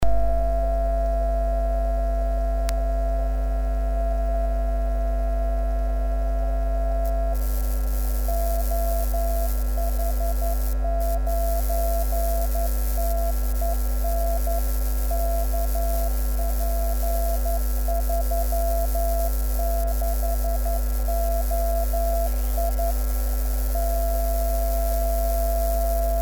10m Baken
Die hier aufgeführten Stationen wurden selbst empfangen.